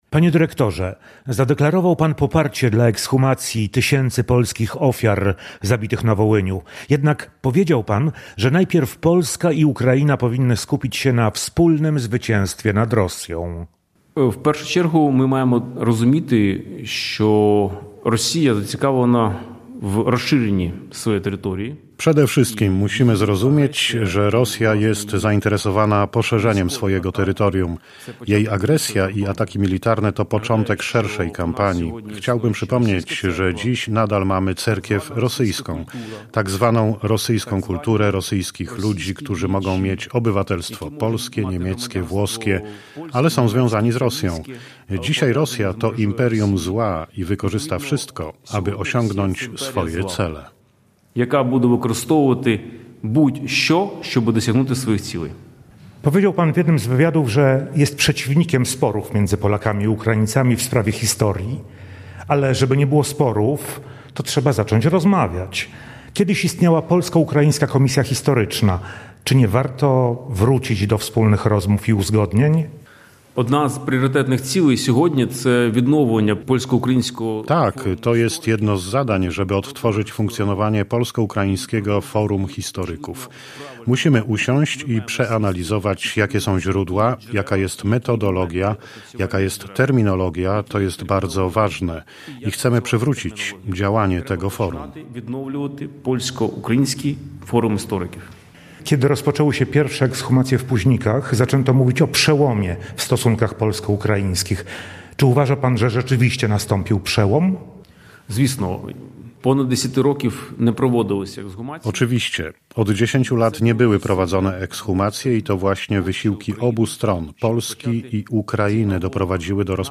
Z Ołeksandrem Ałfiorowem rozmawiał